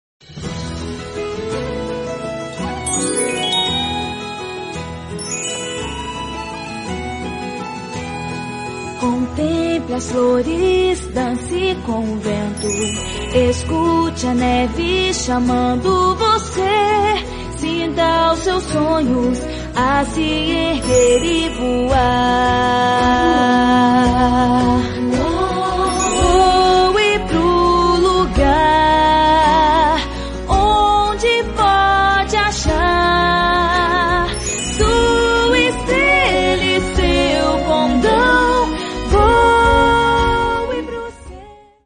🧚‍♀💫 🎶 Música linda 🎬 Animação delicada 💌 Entrega 100% digital Transforme a festa em um momento inesquecível!